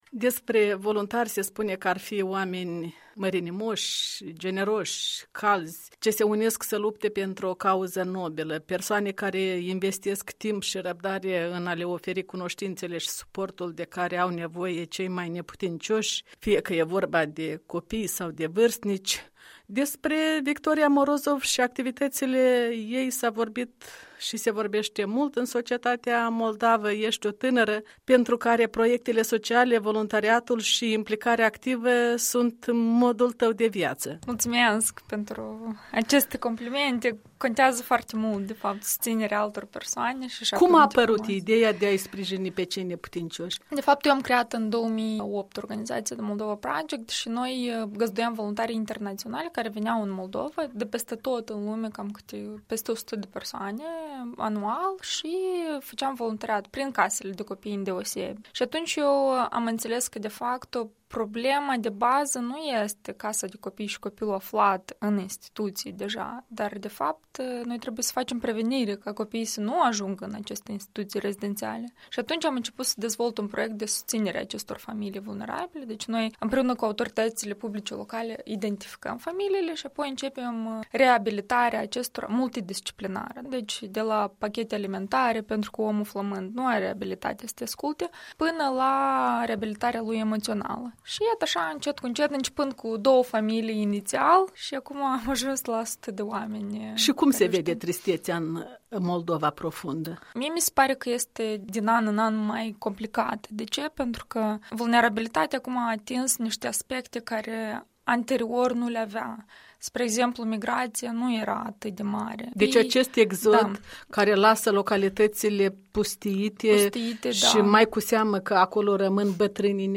Interviu cu fondatoarea unui ONG ce aduce voluntari străini în R. Moldova pentru a ajuta familii vulnerabile.